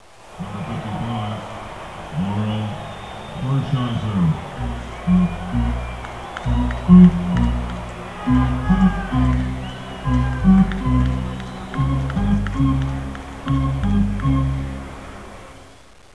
Baseball presentazione speaker
Voce in inglese con folla ed effetti presi da una partita di baseball americano.
Effetto sonoro - Baseball presentazione speaker